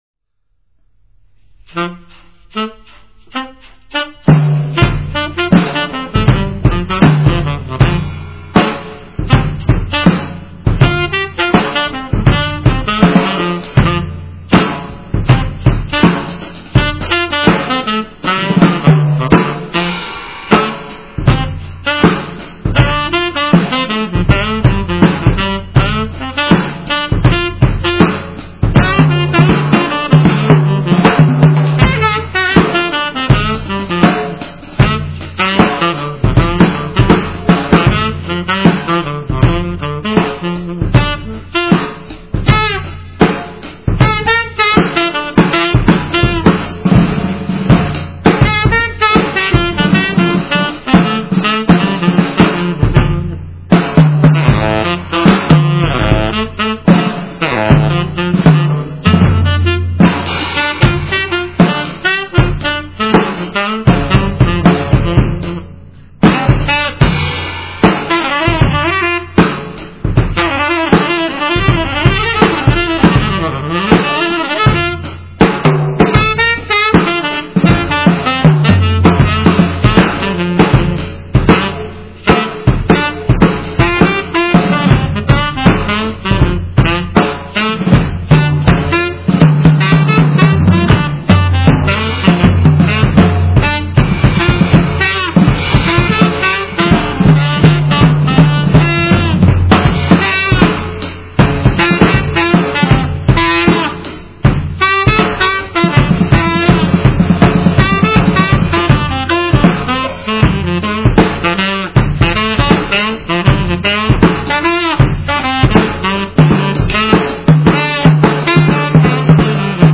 tenor sax
drums.